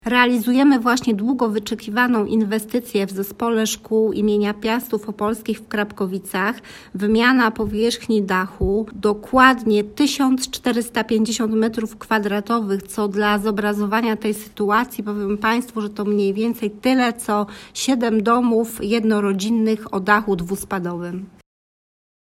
– mówi wicestarosta Sabina Gorzkulla.